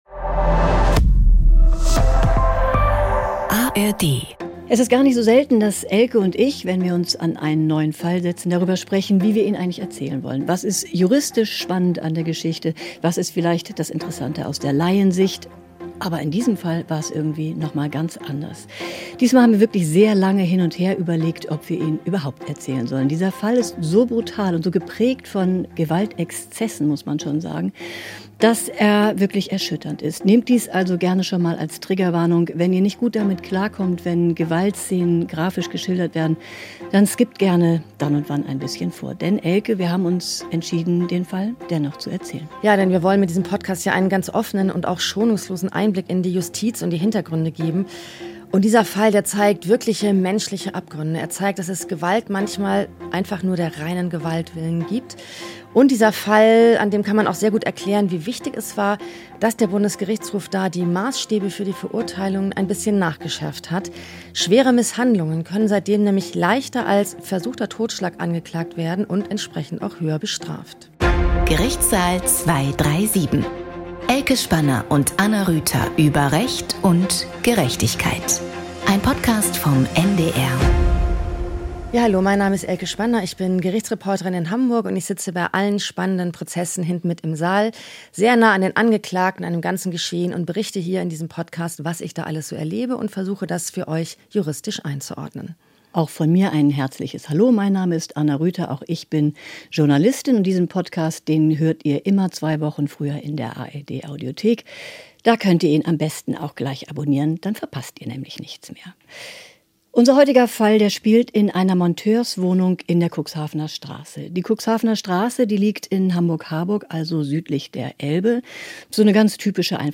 Gerichtssaal 237. True Crime aus dem Strafgericht Sadismus: Folter in der Handwerkerwohnung (31) Play episode September 10 40 mins Bookmarks View Transcript Episode Description Mit Möbeln beworfen und dazu gezwungen sein eigenes Blut aufzuwischen: Mehrere Mitbewohner misshandeln ihren Arbeitskollegen zu Hause wieder und wieder schwer. Journalistin
Gerichtsreporterin